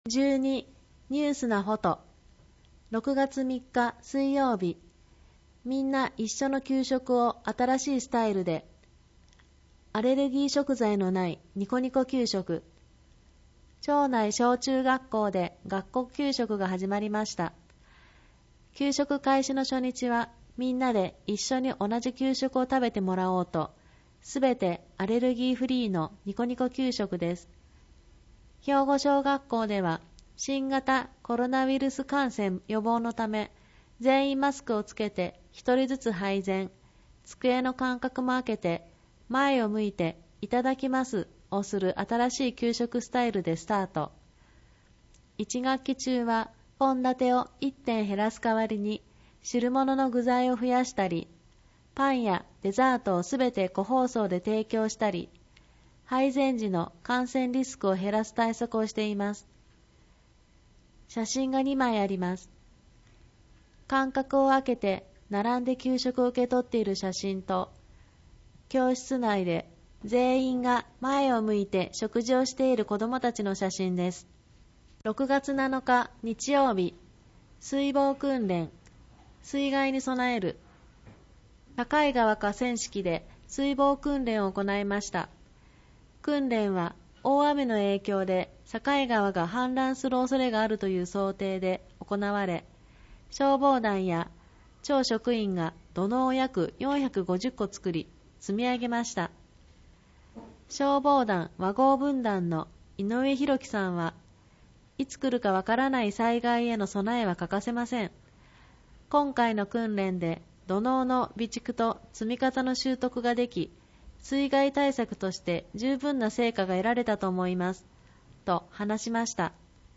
広報とうごう音訳版（2020年7月号）